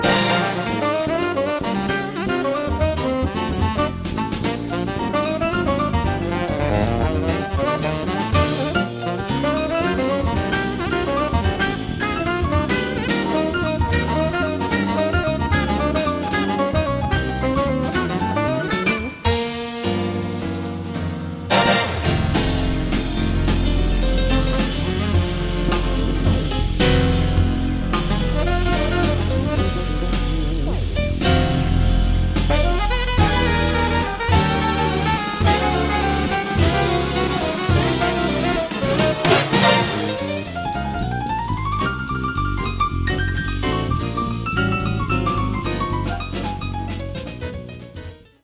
piano and synths
sax
bass
drums